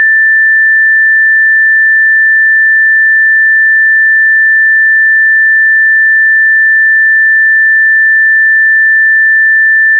A suitable 1770 Hz sine wave can be downloaded
sine-wave-1770-hz.wav